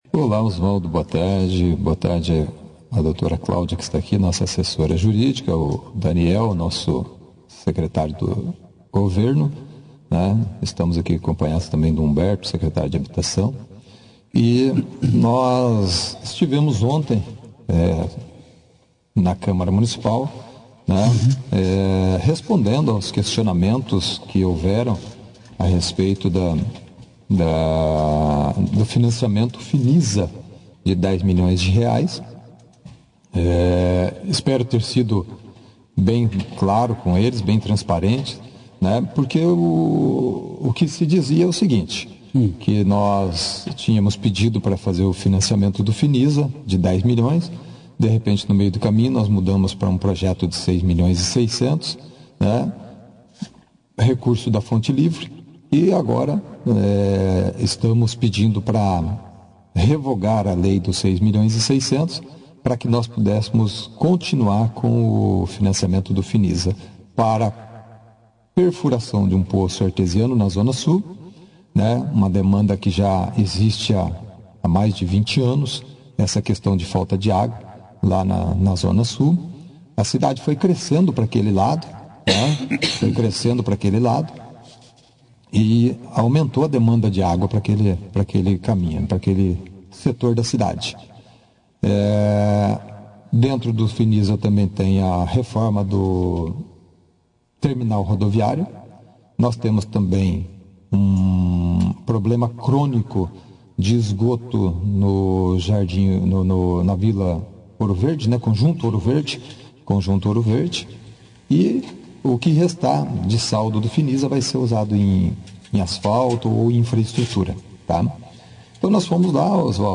O prefeito Jaelson Matta, acompanhado de varios secretários, (foto), participou da 2ª edição do Jornal Operação Cidade nesta quinta-feira, 06/07, para esclarecer sobre um pedido feito à Câmara de Vereadores.